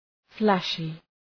Προφορά
{‘flæʃı}